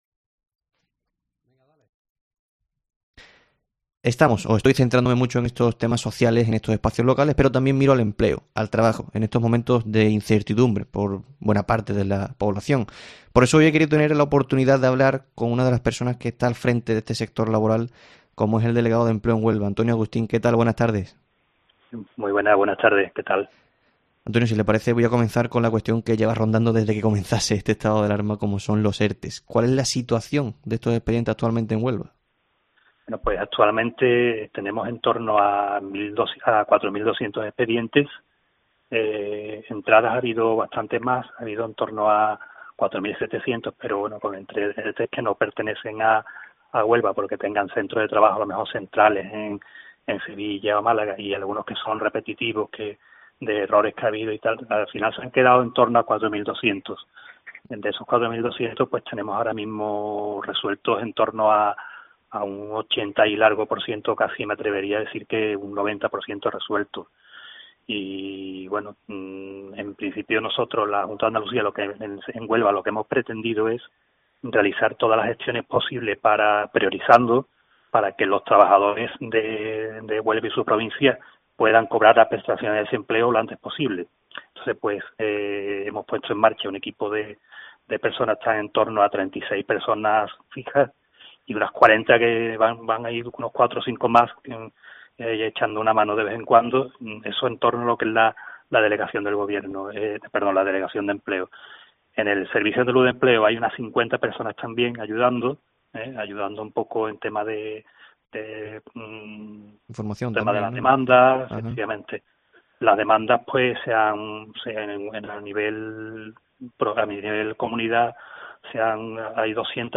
En el tiempo local de Herrera en COPE hablamos con Antonio Augustín, delegado territorial de Empleo en Huelva, que analiza la situación laboral provincial.